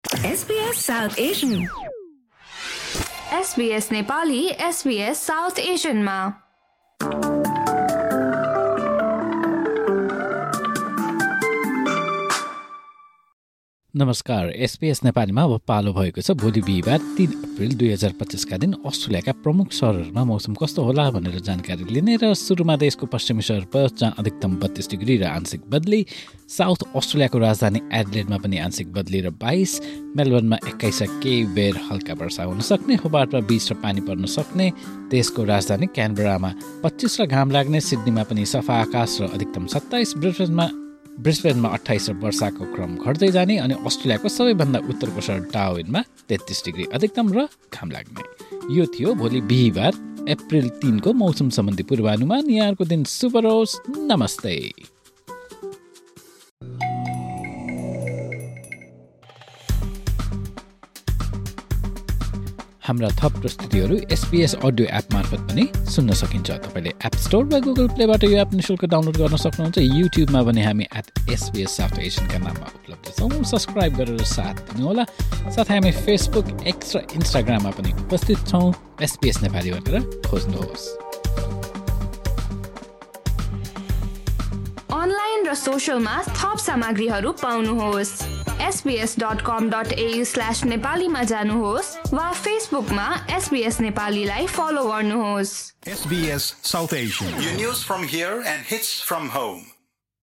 Australian weather update in Nepali for Thursday, 3 April 2025.